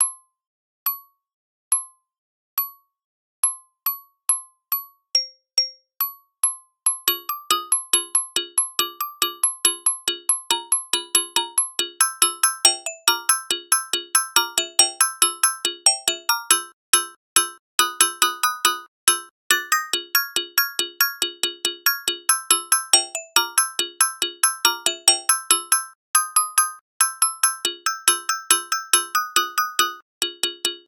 mallet_song_0.ogg